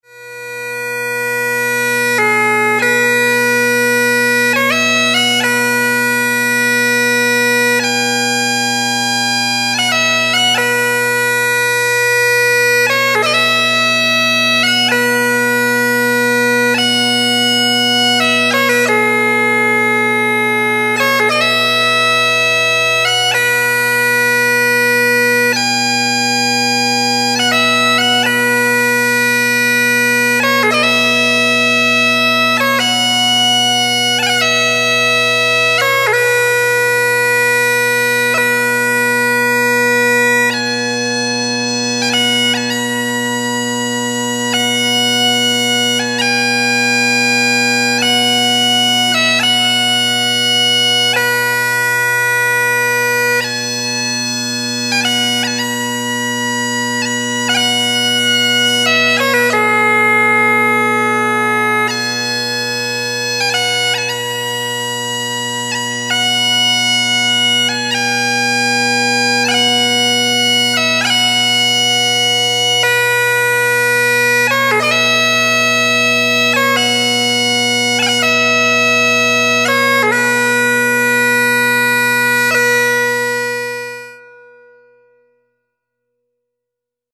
• A very poignant slow air often played at Highland funerals.
Cro-Chinn-t-Saile-The-Cro-of-Kintail-bagpipes.mp3